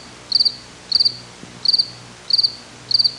Cricket Chirping Sound Effect
Download a high-quality cricket chirping sound effect.
cricket-chirping.mp3